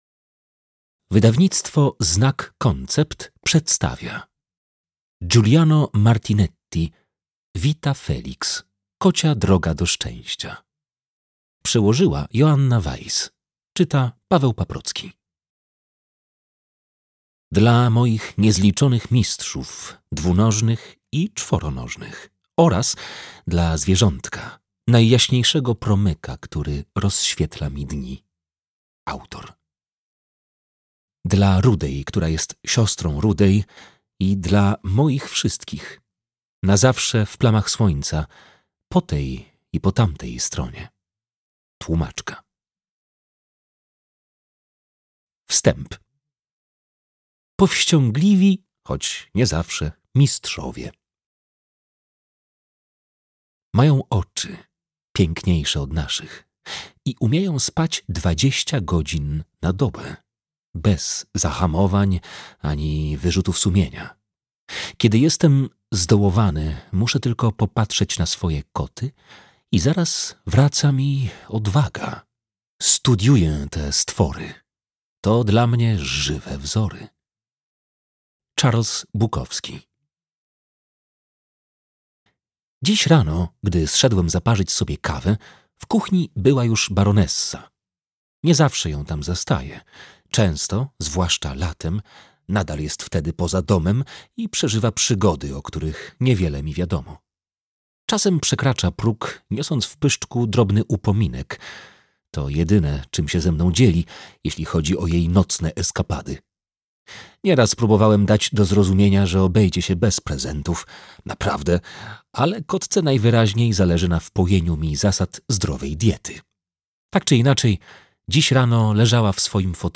Vita Felix. Kocia droga do szczęścia - Giuliano Martinetti - audiobook + książka